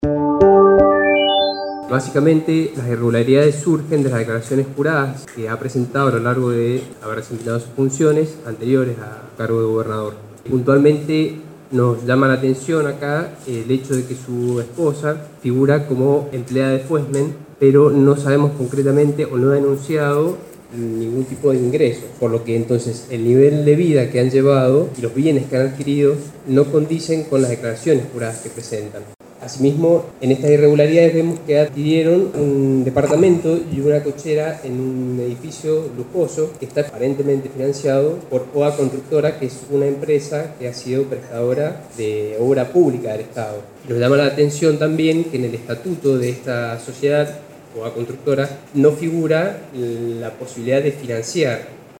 Audio senador Adriàn Reche